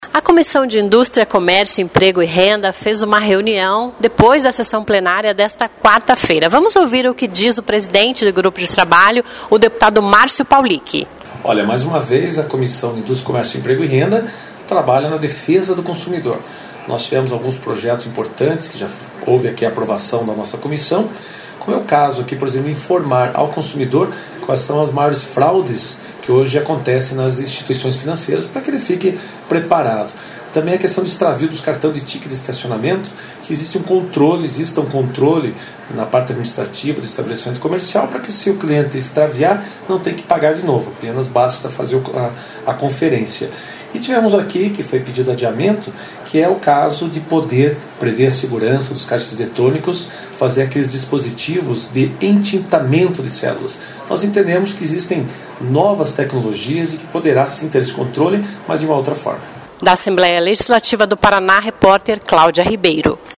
A Comissão  de Indústria, Comércio, Emprego e Renda se reuniu depois da sessão Plenário desta quarta-feira (7). E a gente conversa agora com o presidente do grupo de trabalho, o deputado Márcio Pauliki (PDT).
(Sonora)